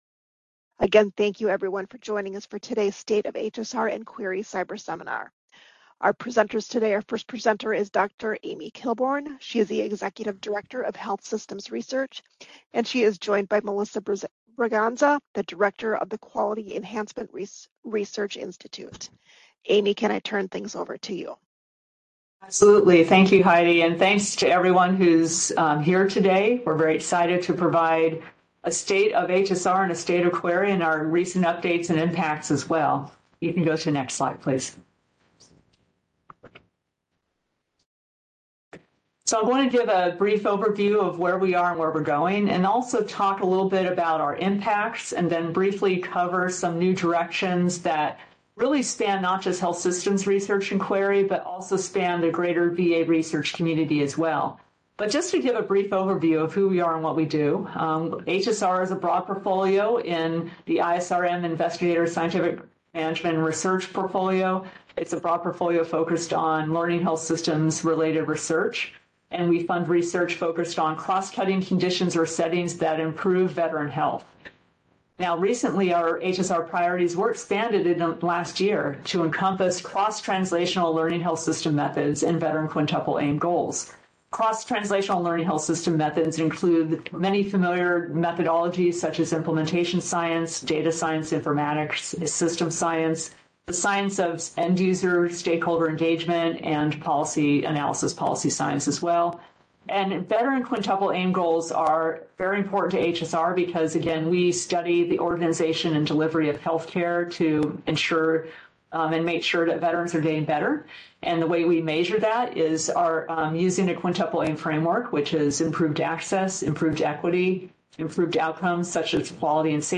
HSR Administration Seminar
Description: Health Systems Research (HSR)/Quality Enhancement Research Initiative (QUERI) National Program Office leaders will present on HSR/QUERI priorities, strategic goals, and opportunities. The Cyberseminar will highlight HSR/QUERI’s impact on improving Veteran health, describe future directions for HSR/QUERI in aligning research, evidence-based policymaking, and quality improvement efforts, and discuss efforts to enhance diversity, equity, inclusion, and accessibility in HSR/QUERI.